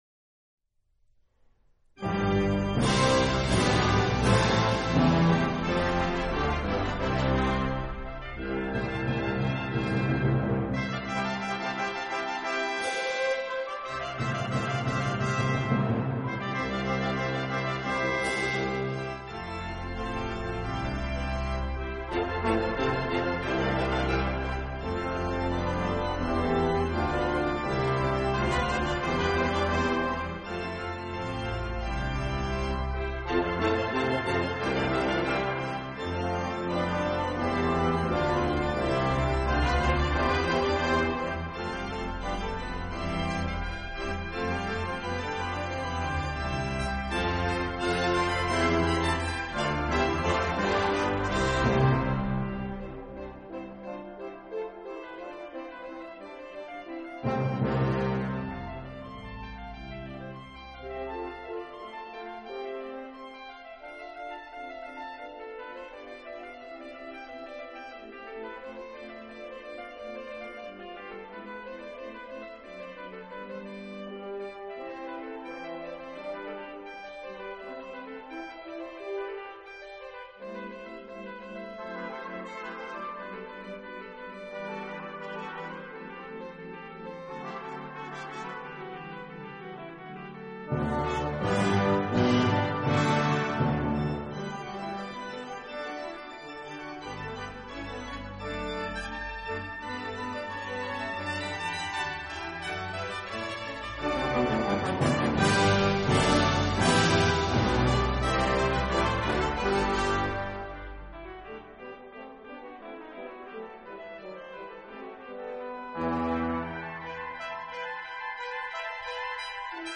Meyerson Symphony Center, Dallas TX
因为除了管乐与大鼓的优秀表现之外，还加入了震慑人心的管风琴。
是经常震慑人心，它也表现出很多的柔情。